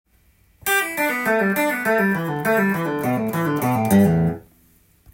プリング練習tab譜
譜面通り弾いてみました